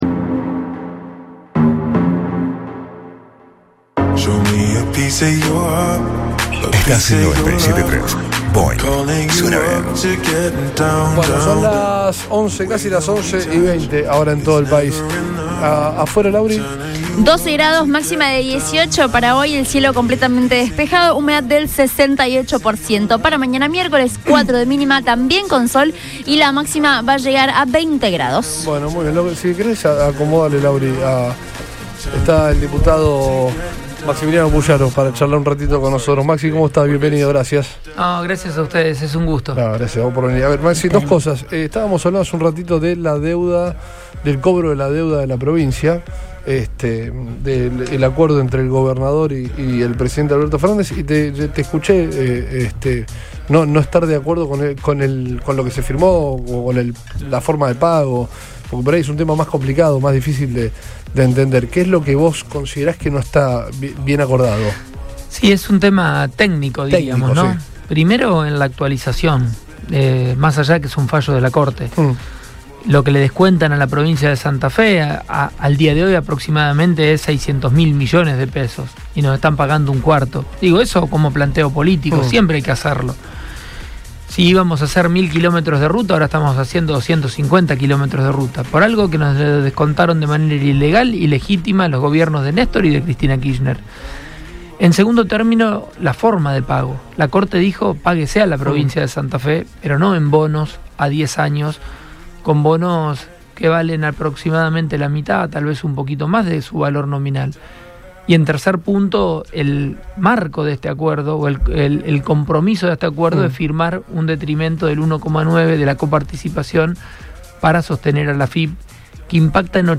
El diputado provincial Maximiliano Pullaro habló